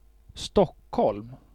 Stockholm (/ˈstɒkh(l)m/;[10] Swedish: [ˈstɔ̂kː(h)ɔlm]
Sv-Stockholm.ogg.mp3